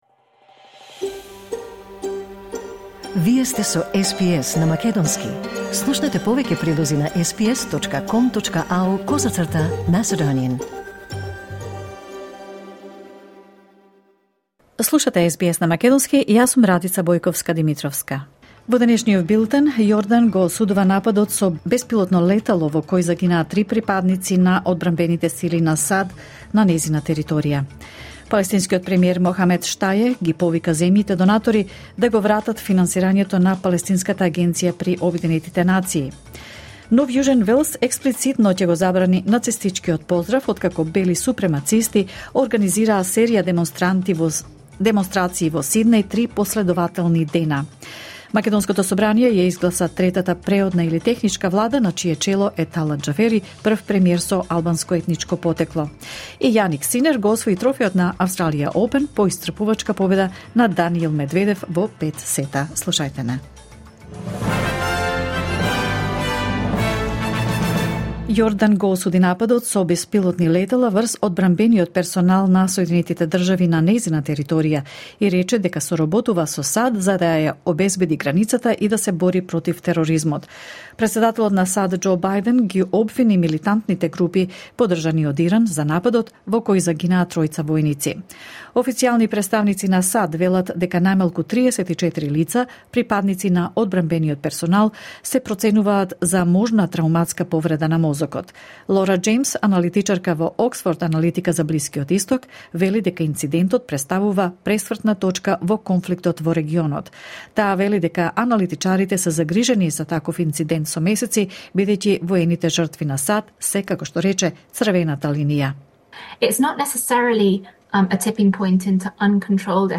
SBS News in Macedonian 29 January 2024